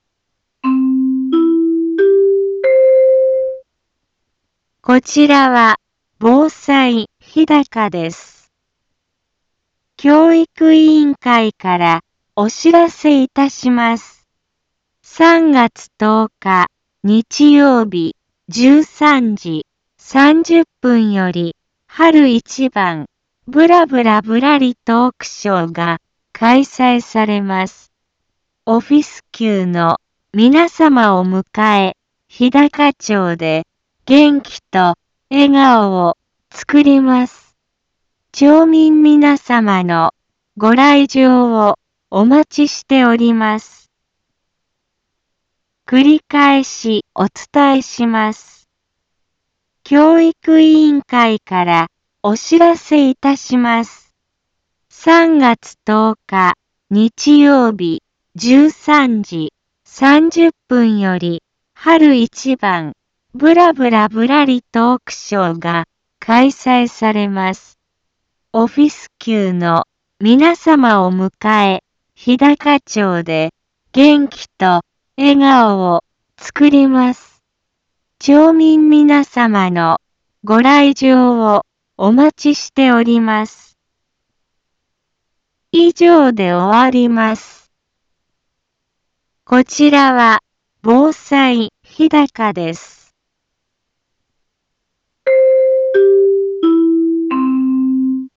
Back Home 一般放送情報 音声放送 再生 一般放送情報 登録日時：2019-03-06 15:03:31 タイトル：春一番ぶらぶらぶらりトークショー開催のお知らせ。